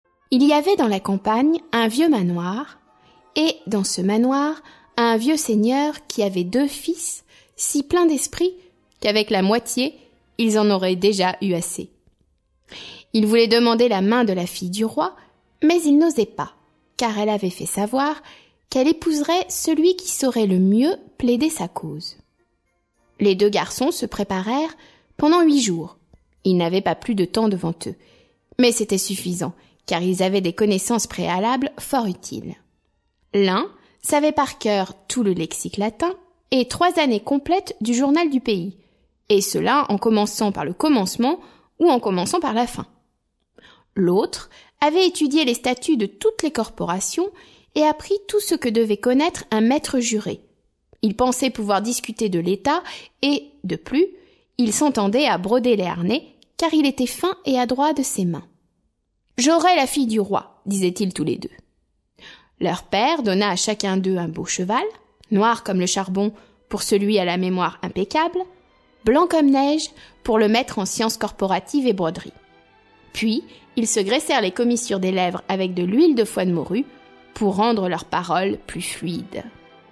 Diffusion distribution ebook et livre audio - Catalogue livres numériques
Musique : Rimsky Korsakov